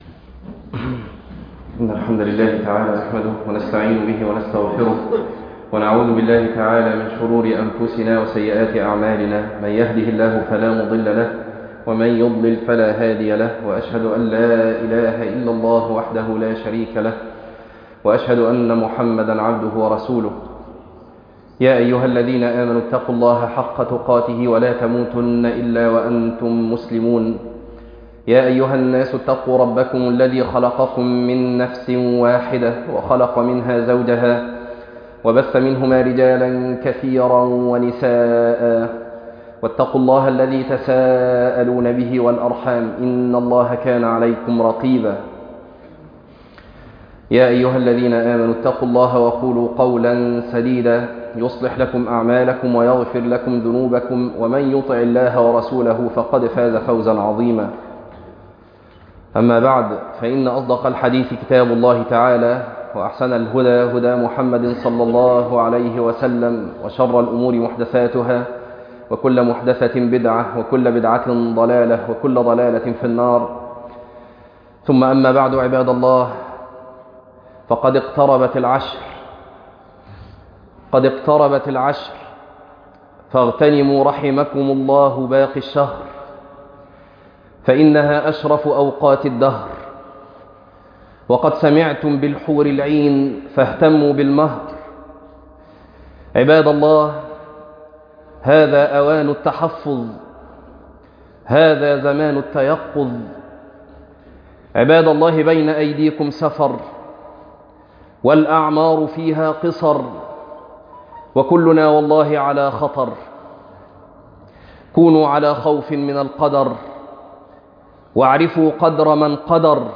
تفاصيل المادة عنوان المادة العشر الأواخر وليلة القدر - خطبة تاريخ التحميل الأحد 12 ابريل 2026 مـ حجم المادة غير معروف عدد الزيارات 19 زيارة عدد مرات الحفظ 8 مرة إستماع المادة حفظ المادة اضف تعليقك أرسل لصديق